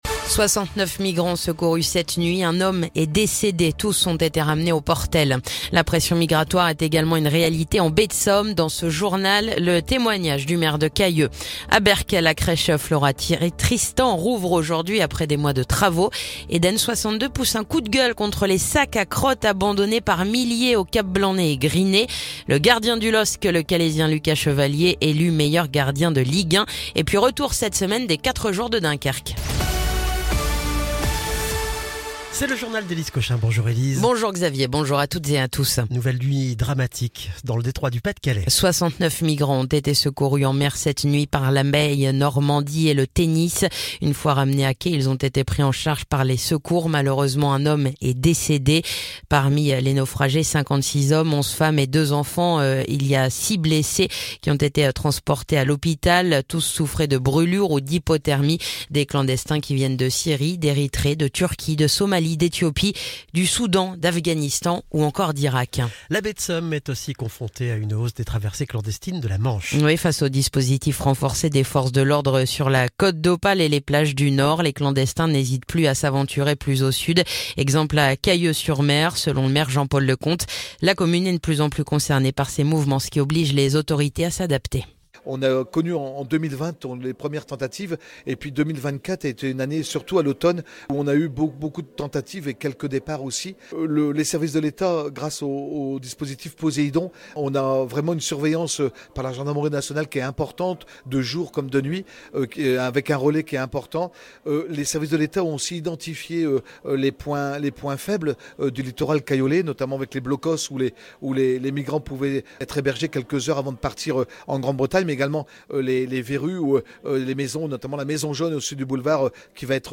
Le journal du lundi 12 mai